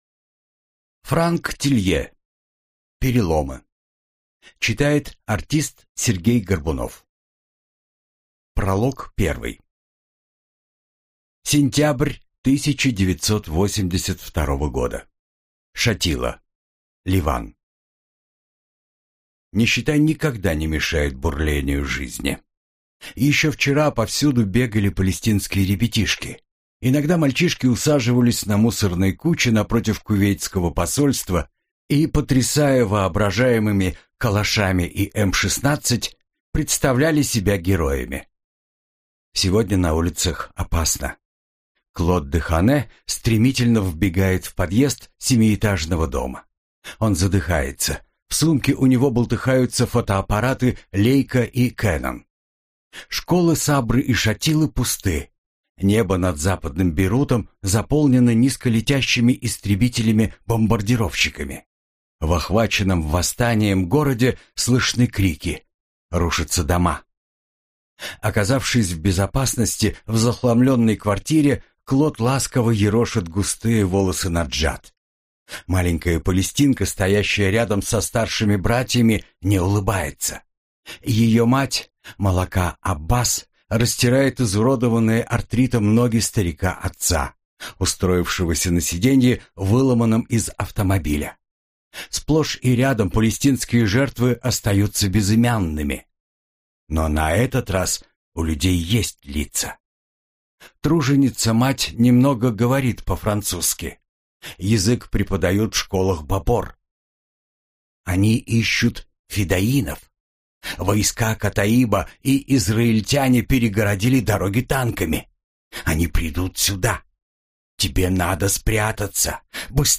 Аудиокнига Переломы - купить, скачать и слушать онлайн | КнигоПоиск